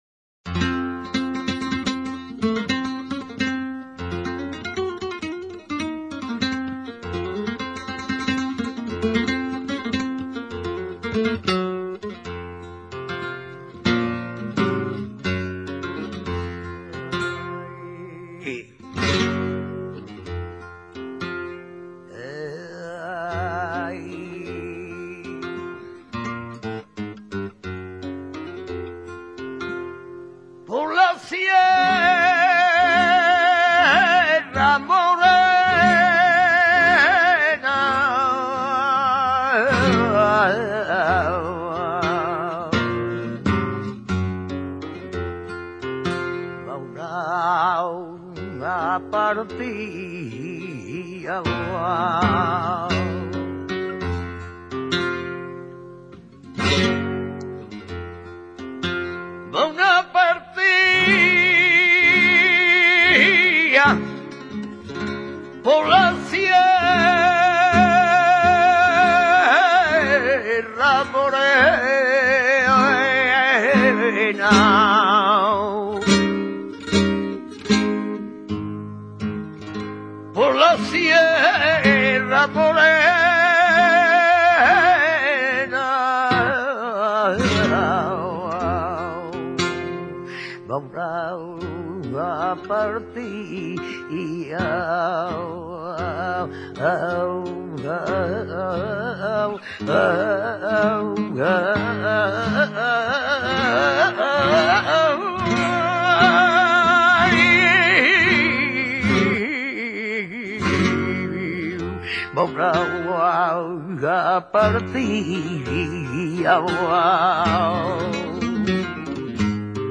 Es un cante valiente, de mucho br�o, extraordinariamente melodioso y pegadizo, de frases largas y solemnes, que exige poderosas facultades para ser interpretado.
En su melod�a pueden descubrirse aires de ca�a, de siguiriya y, sobre todo, de liviana con la que, musicalmente, el parentesco es patente. Se acompa�a de guitarra, con toque de siguiriya en tono de La.
serrana.mp3